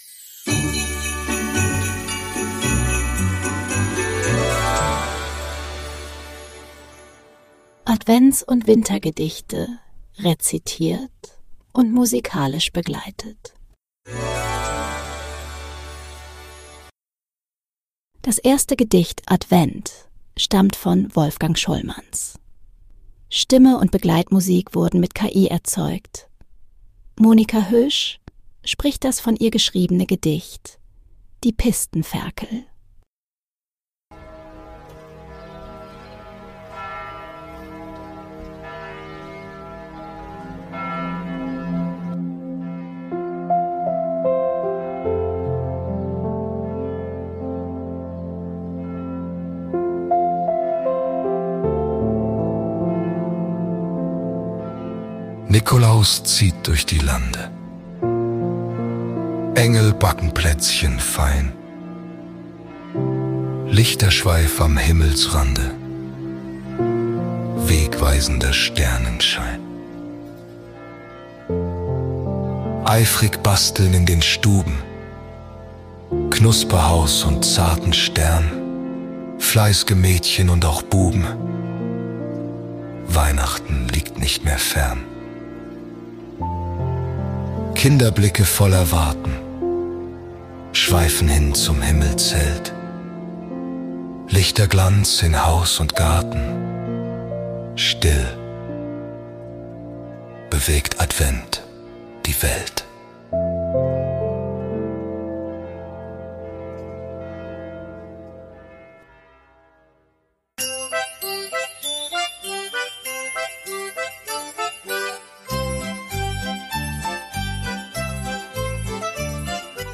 musikalische Begleitung wurden mit KI erzeugt.